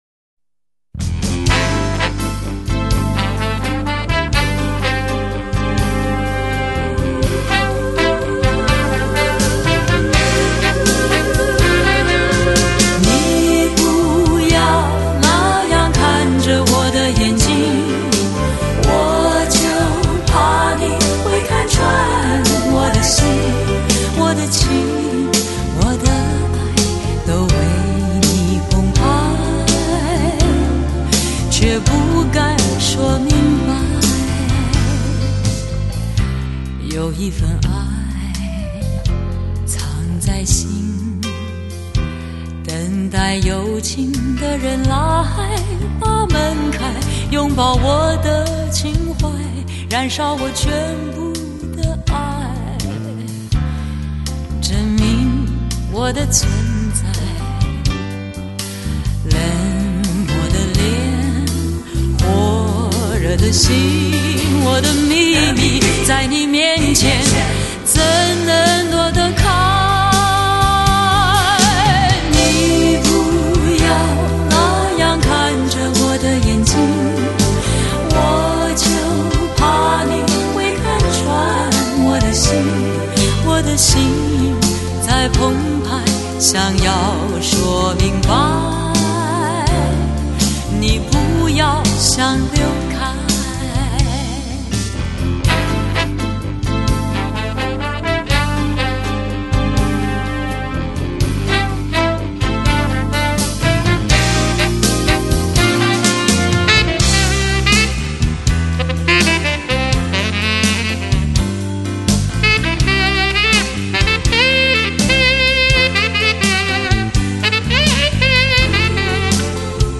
始终以她那独具风韵的低沉磁性噪音令诸多歌迷陶醉。
她得天独厚的女中音，
淡淡的感伤在舒缓的配乐中，
沉静的，恬淡的，波澜不惊，